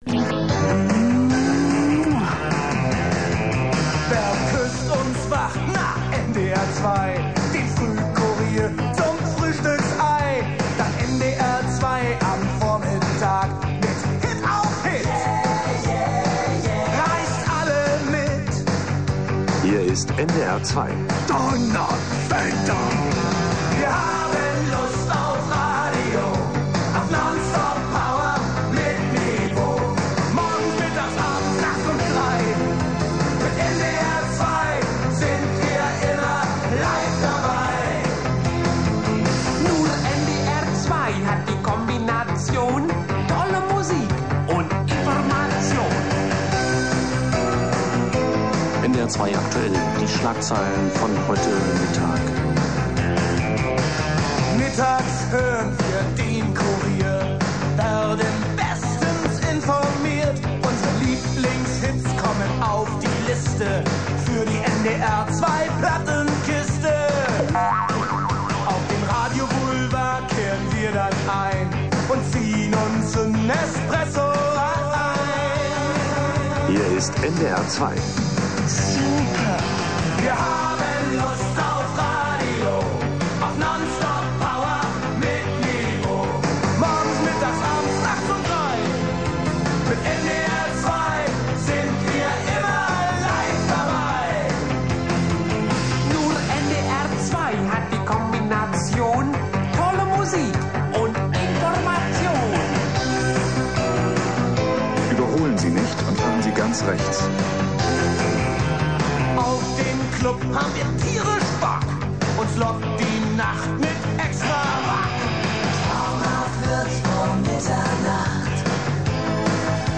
Stationsong
Viel Spaß dabei und entschuldigt die schlechte Qualität.